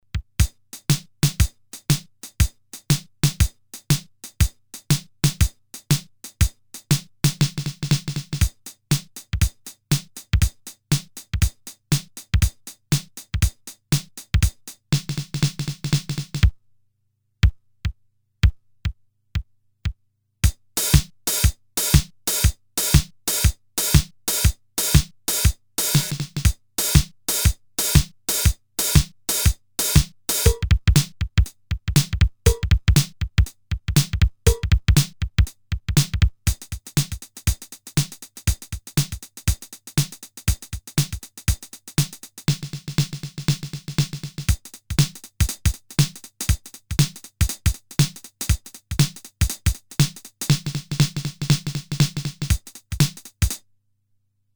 A kind of preset version of classic drum machine TR-505 with same samples soundset but inexplicably lacking the great sequencer.
Internal (non-expandable) PCM are read from a single 128 kb ROM (at IC12) which contains 16 non-editable percussive sounds recorded at 8 bits /25 kHz rate
CPu Sound list: kick, snare, rimshot, clap, tom (x3), timbale, hh (open/close), crash, cymbal, tambourine, claves and conga (x2).
some preset rhythms p1
Class: drum machine